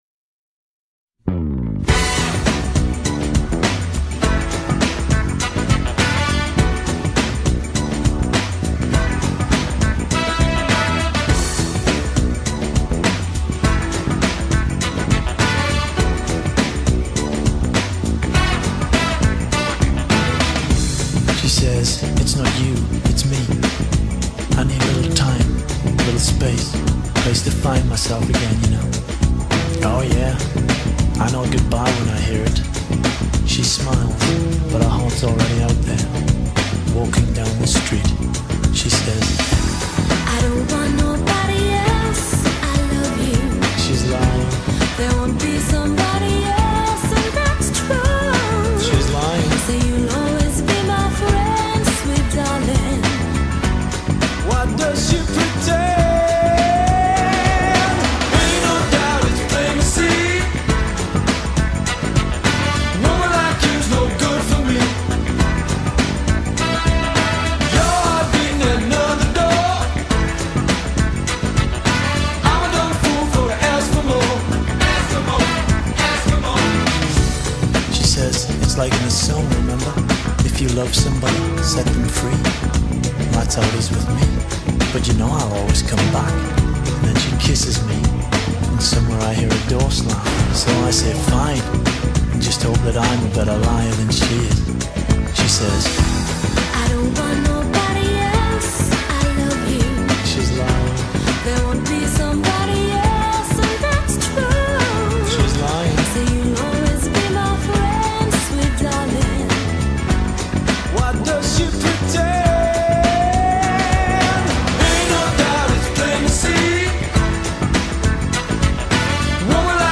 90's UK soft